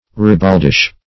Ribaldish \Rib"ald*ish\, a.
ribaldish.mp3